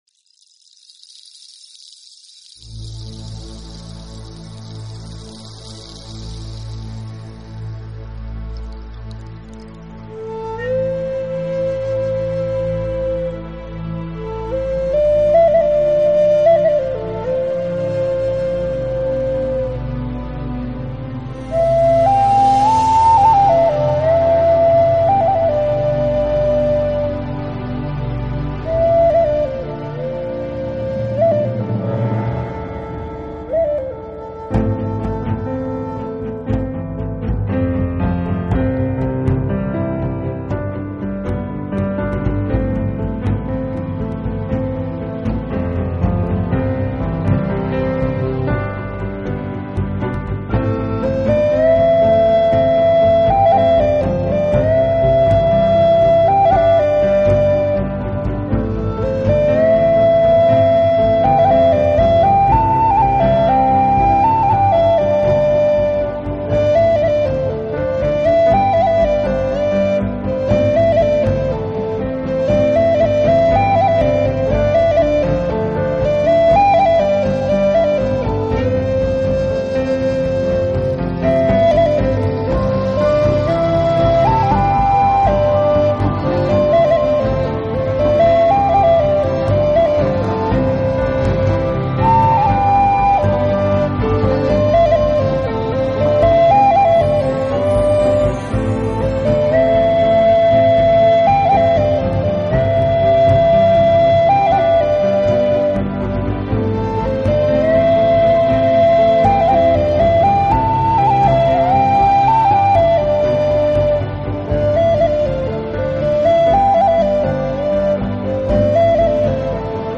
音乐流派：Newage/Instrumental
最大的特色就是电子乐和交响乐的融合，交织出丰富鲜明的场